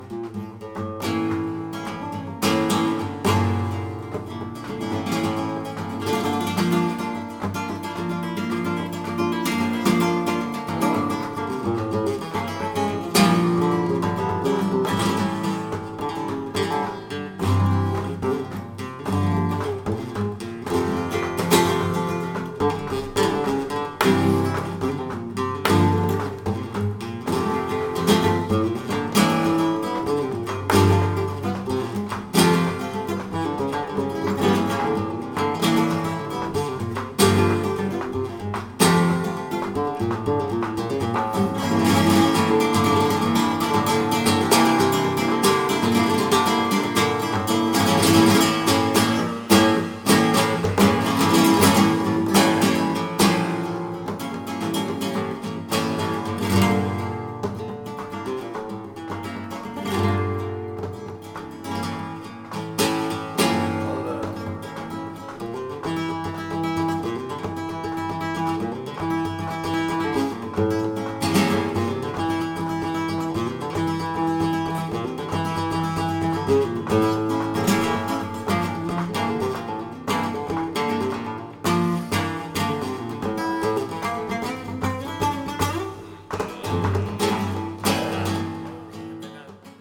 Palosanto y pino aleman flamenco guitar
buleria.mp3